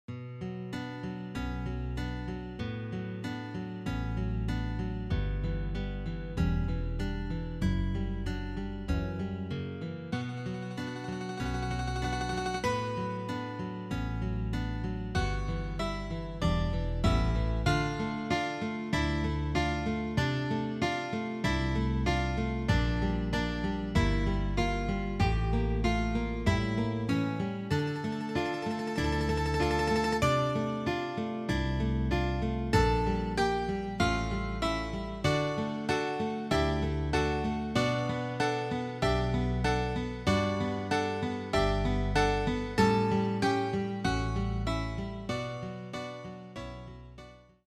Orquesta de Plectro Guion + partes 16 p. Nivel